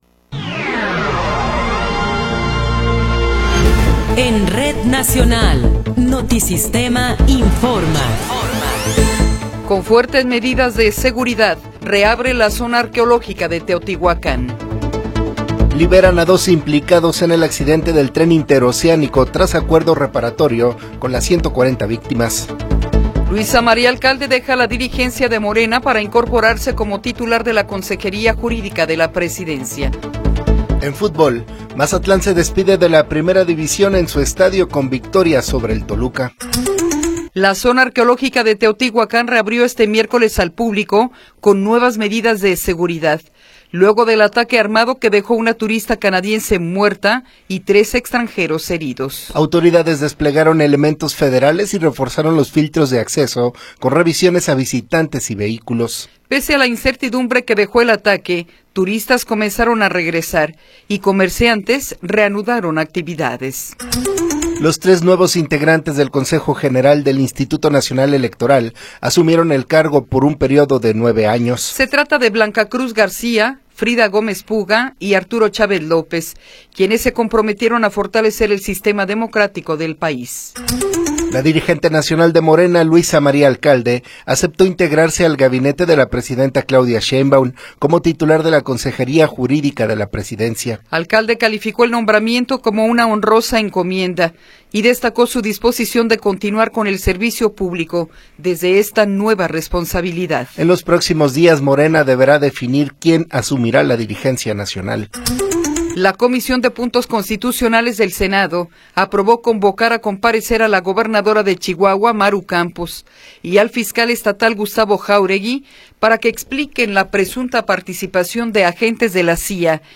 Noticiero 8 hrs. – 23 de Abril de 2026